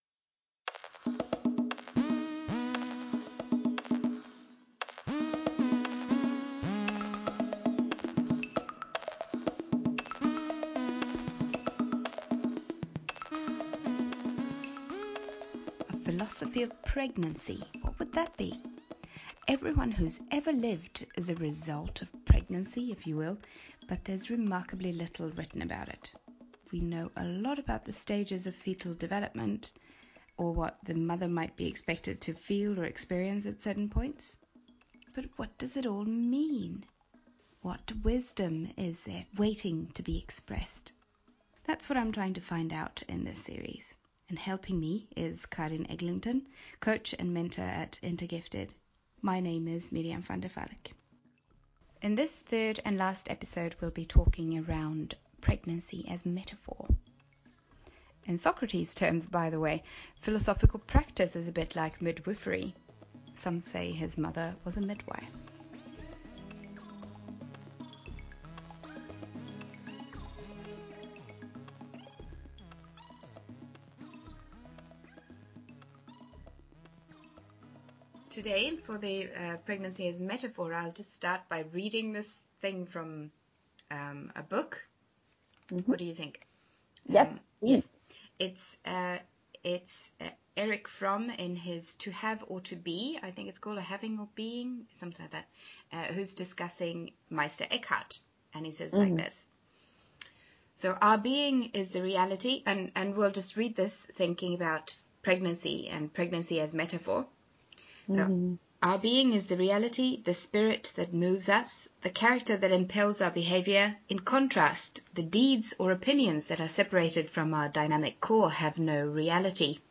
Three conversations on the Philosophy of Pregnancy